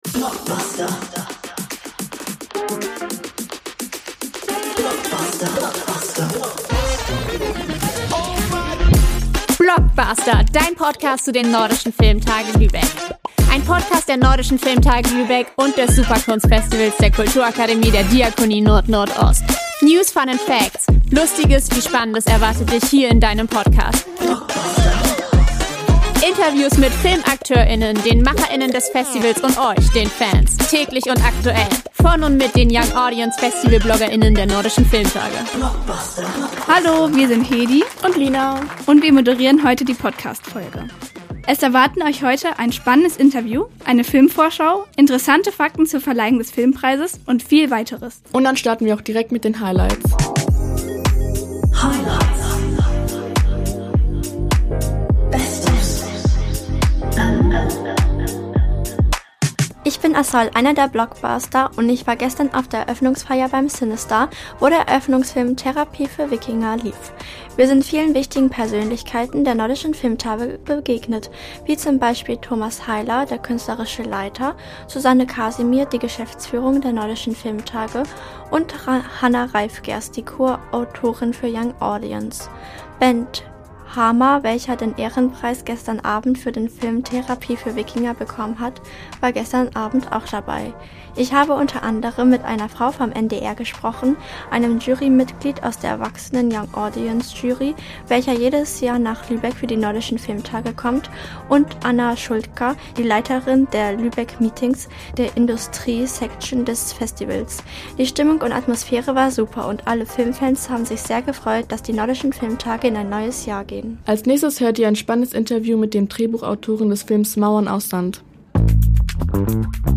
In der zweiten Folge nehmen wir euch mit zur Eröffnungsfeier